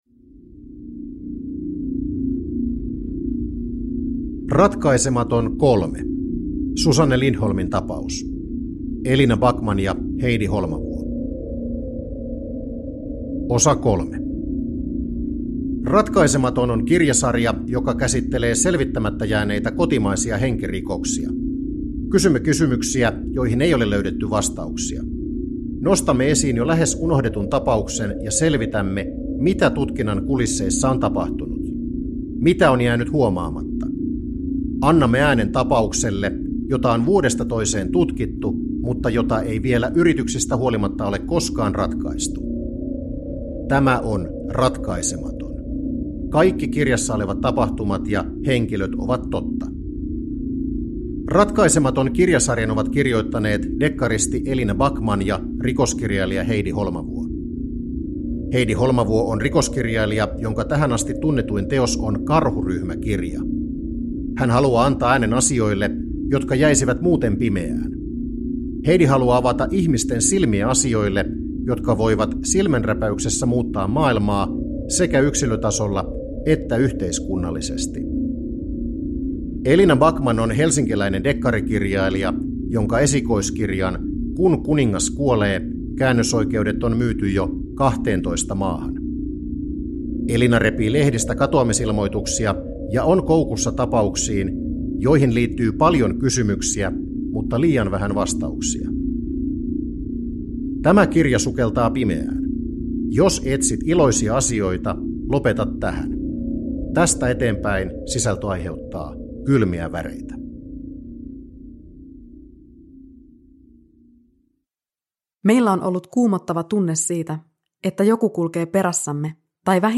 Ratkaisematon 3 (ljudbok) av Heidi Holmavuo